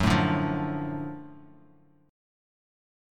F#mM7#5 chord